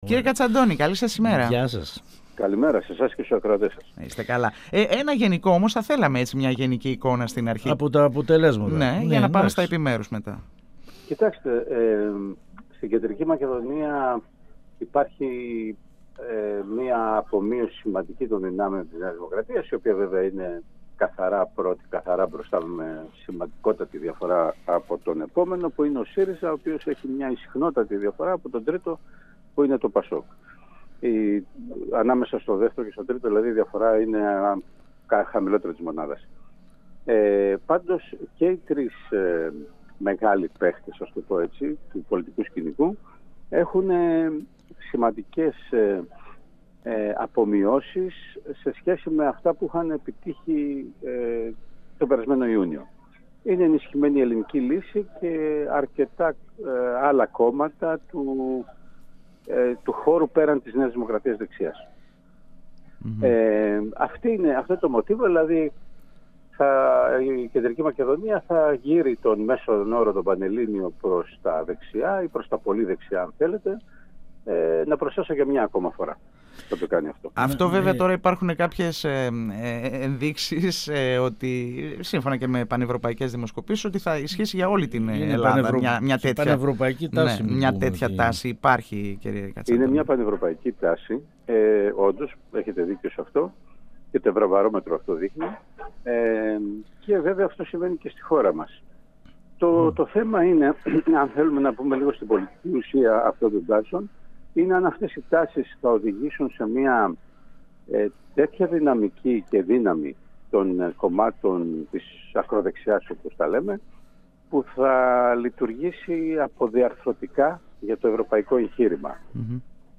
ο οποίος μίλησε στην εκπομπή «Εδώ και Τώρα» του 102FM της ΕΡΤ3.